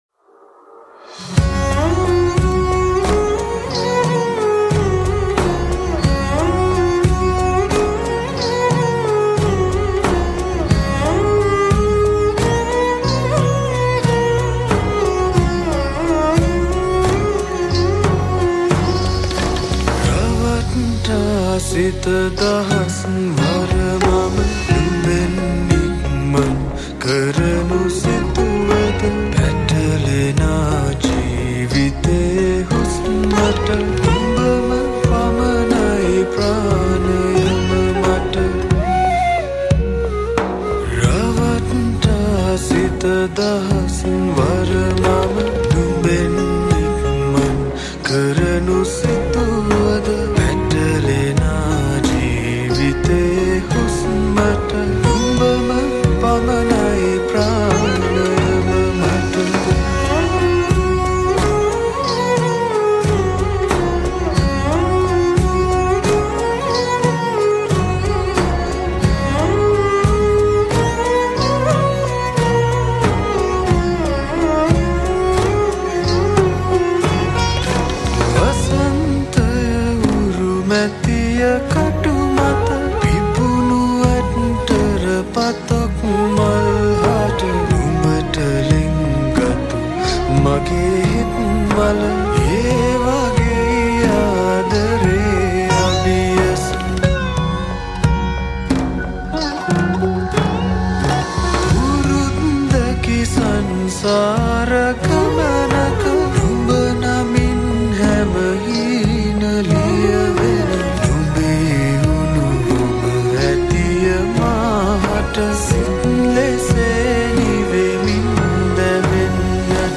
Esraj & Sitar
Flute